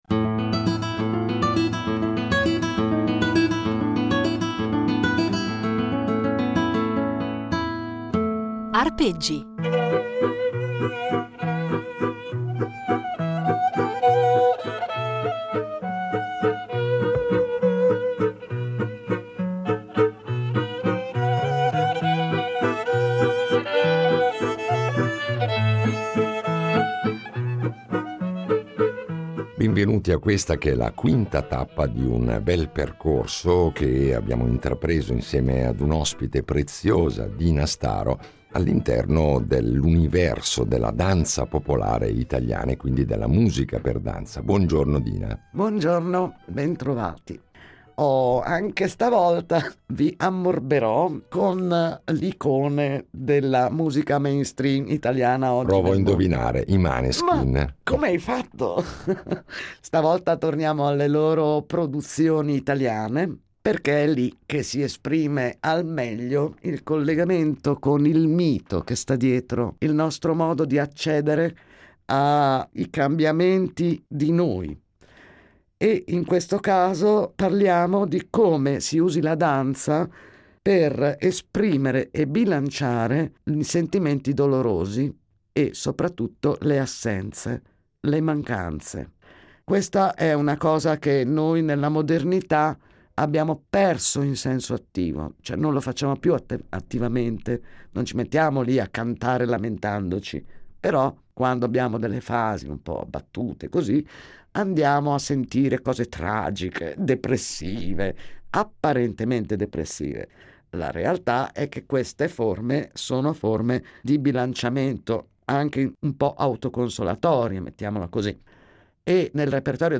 Grazie alla sua passione e alla sua competenza, cercheremo di capire perché l’uomo sente da sempre il bisogno di riunirsi per il rito della danza, scopriremo quali sono le importanti eredità storiche delle varie tradizioni e impareremo a conoscere le forme musicali che le alimentano. Un itinerario sonoro ricco di materiale inedito, registrato sul campo e negli anni da lei stessa